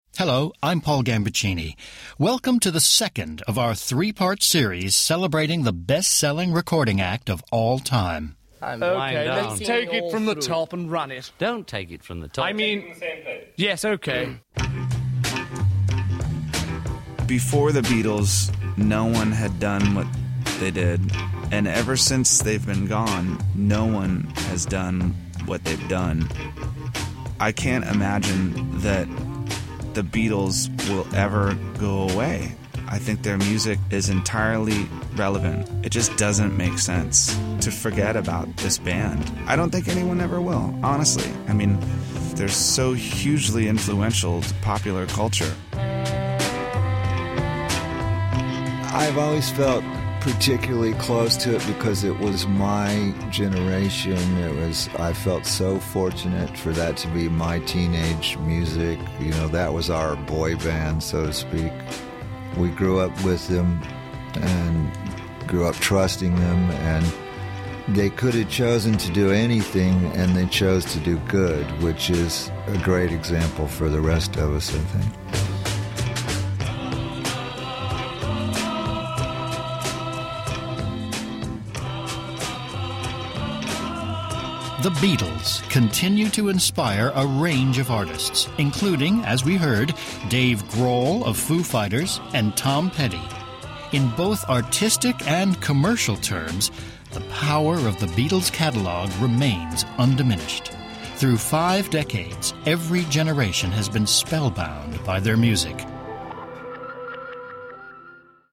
The series is narrated by veteran British presenter and music writer Paul Gambaccini.